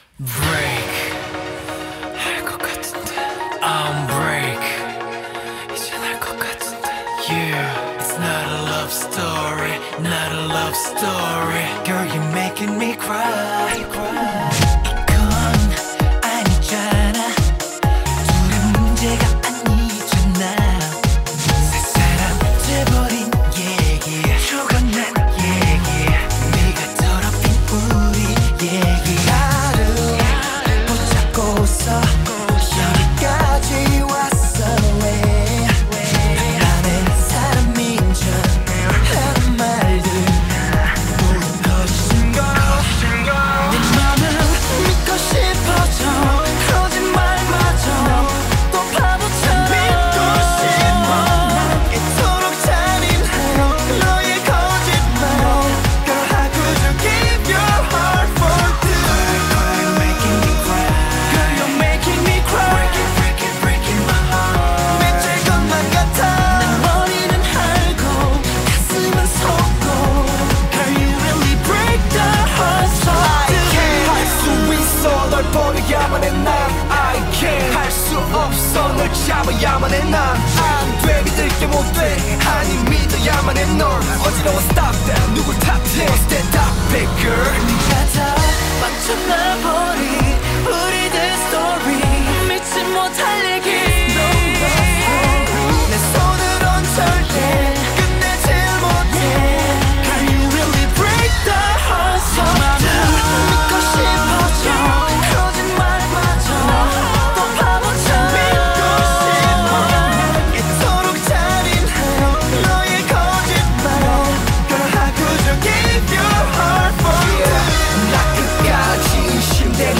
BPM66-132
Audio QualityPerfect (Low Quality)